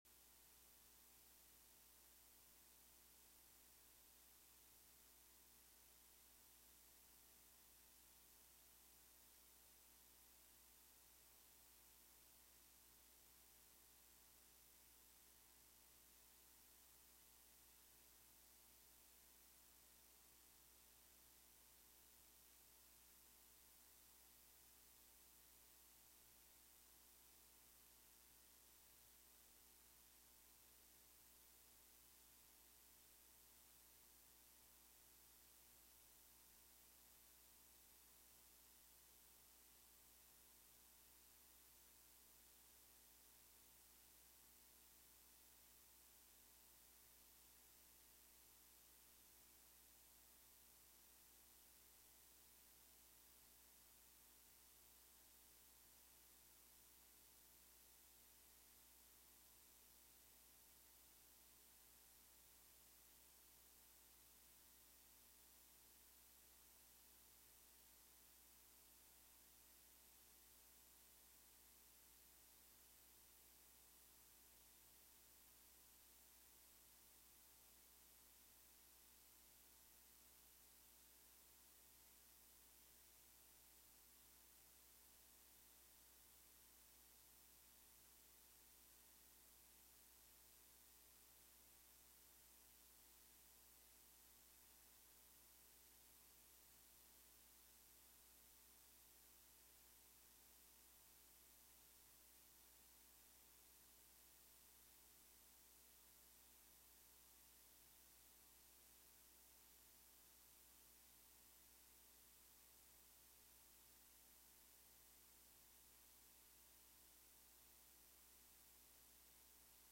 Sermon Series: Love Gifts: What Matters Most – Part 3